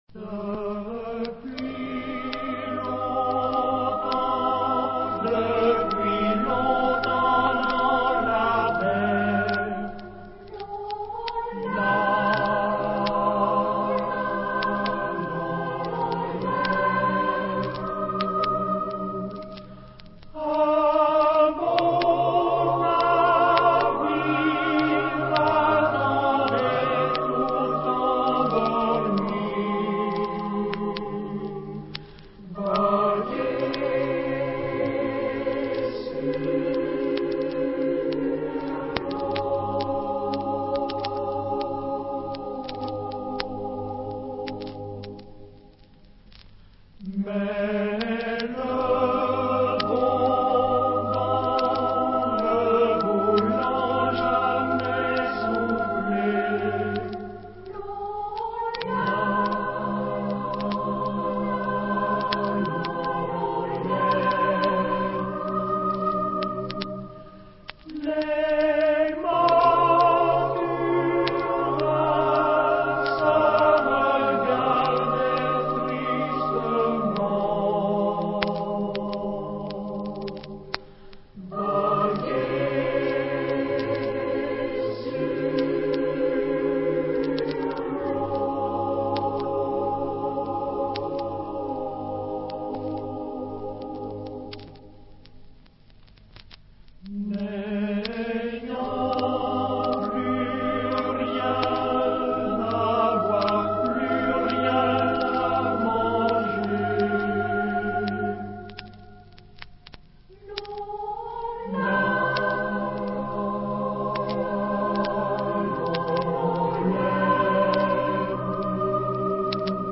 Genre-Style-Forme : Populaire ; Profane
Type de choeur : SATB  (4 voix mixtes )
Solistes : Tenor (1)  (1 soliste(s))
Tonalité : fa majeur